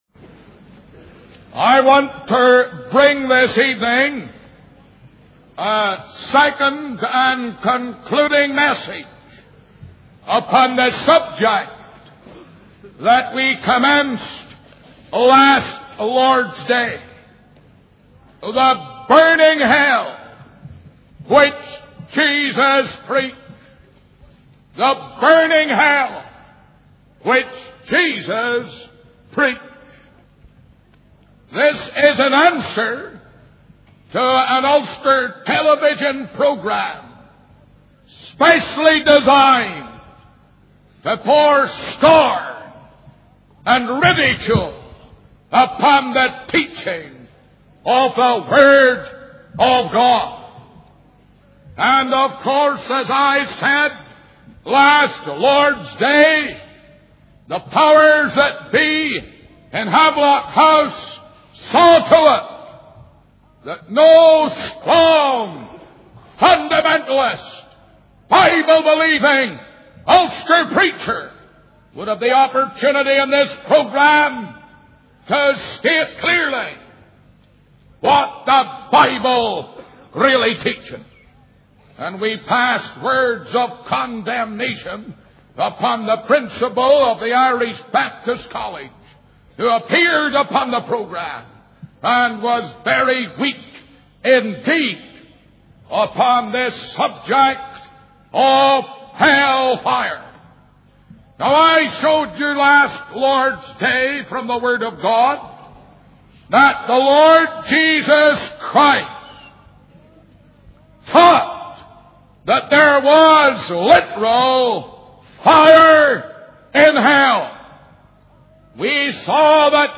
In this sermon, the preacher addresses the topic of hell and responds to a television program that ridiculed the teaching of the Word of God. He emphasizes the eternal nature of hell, stating that there is no escape or hope for those who are condemned. The preacher urges the listeners to repent and seek God's mercy, warning them of the consequences of rejecting Christ.